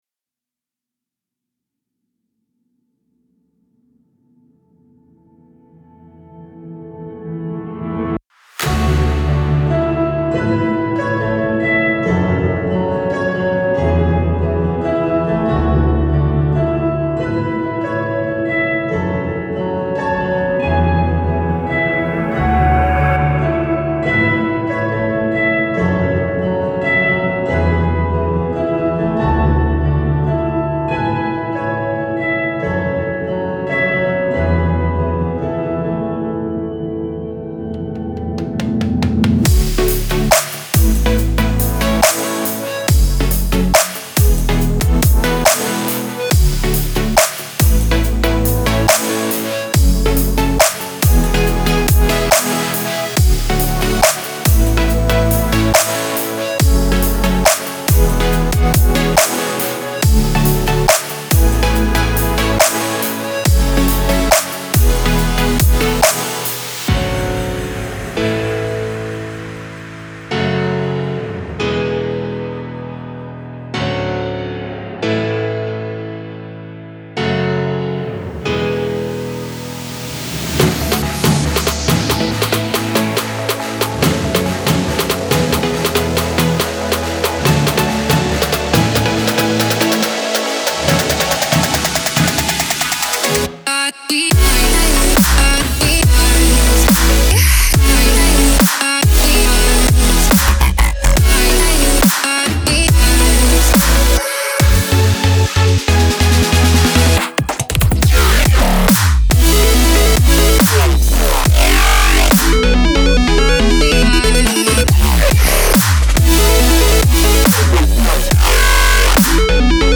Download Instrumental Version